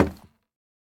Minecraft Version Minecraft Version snapshot Latest Release | Latest Snapshot snapshot / assets / minecraft / sounds / block / bamboo_wood / step2.ogg Compare With Compare With Latest Release | Latest Snapshot
step2.ogg